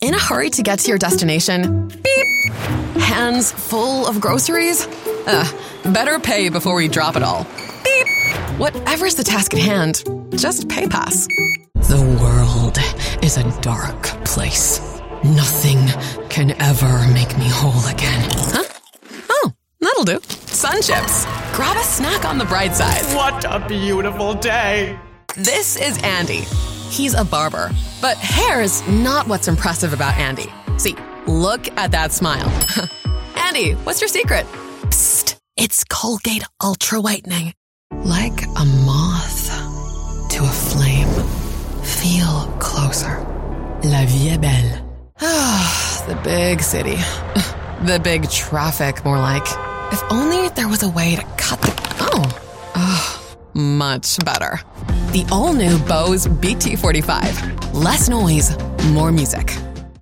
English (International)
English (British)
English (New Zealand)
Approachable Articulate Conversational